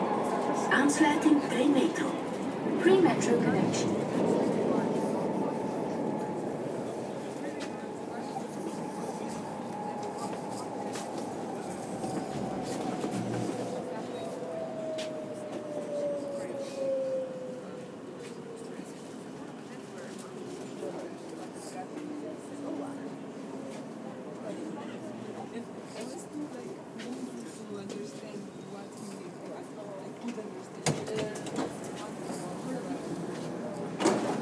brussels - premetro connexion